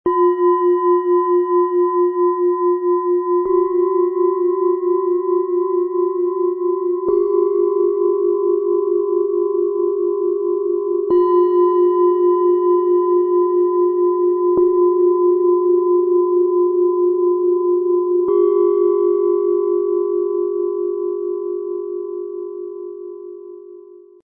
Energie & Klarheit - Klangschalen-Set für frischen Schwung aus 3 Klangschalen, Ø 11,8 - 13,3 cm, 1,11 kg
Zwei fast identische Schalen (je ca. 400 g, Ø 12-14 cm) erzeugen zusammen eine lebendige Klangwelle - wie ein klarer Puls, der frischen Schwung gibt und innere Starre löst.
Die dritte Schale (ca. 310 g) setzt den Akzent: Ihr klarer, funkelnder Ton öffnet den Kopfbereich und bringt Fokus und geistige Frische.
Besonders das Zusammenspiel der beiden großen Schalen klingt wie ein klarer Puls, der belebt und aktiviert.
Ihr sehr hoher, funkelnder Ton wirkt klärend und ausrichtend.
• Tiefster Ton: Bengalen Schale, Glänzend, 13,2 cm Durchmesser, 6,3 cm Höhe, 395,2 Gramm - Region: Bauch, Becken
• Mittlerer Ton: Bengalen Schale, Glänzend, 13,3 cm Durchmesser, 6,6 cm Höhe, 400,6 Gramm - Region: Bauch, Herz, Schulter
• Höchster Ton: Bengalen Schale, Glänzend, 11,8 cm Durchmesser, 5,5 cm Höhe, 310,1 Gramm - Region: Kopf
MaterialBronze